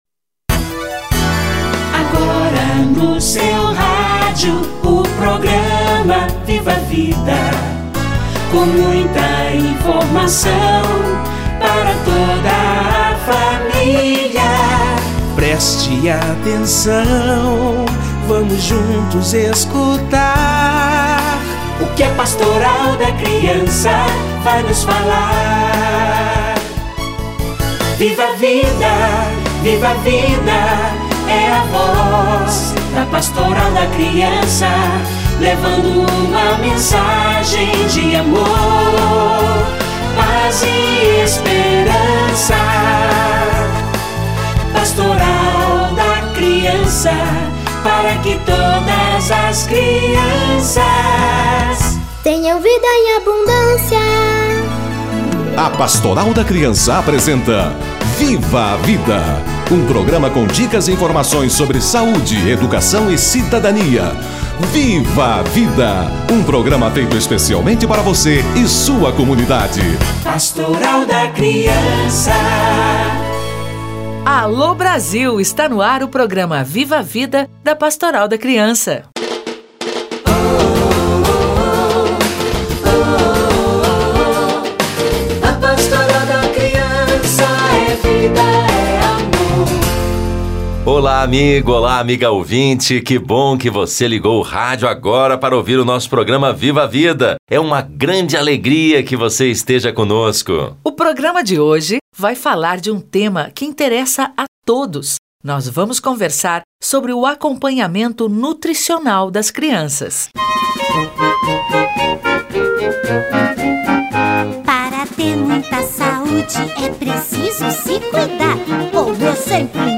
Acompanhamento nutricional - Entrevista